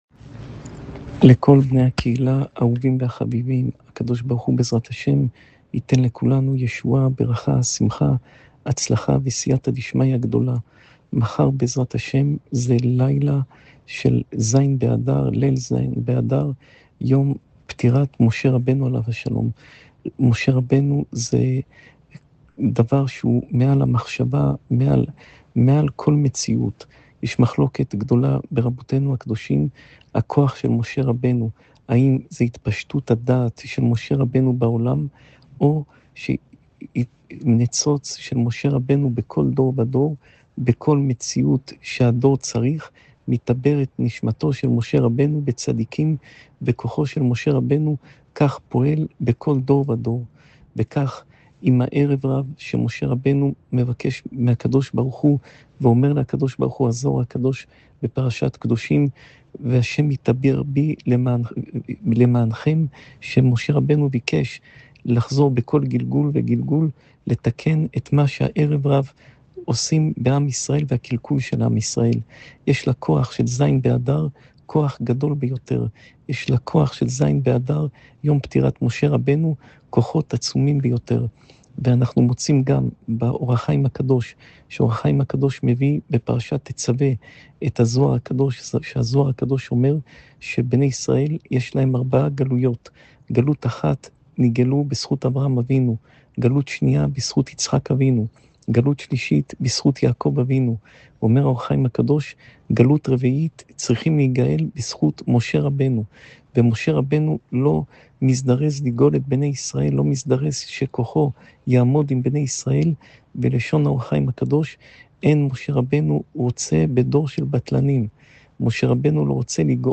שעור תורה מפי הרב פינטו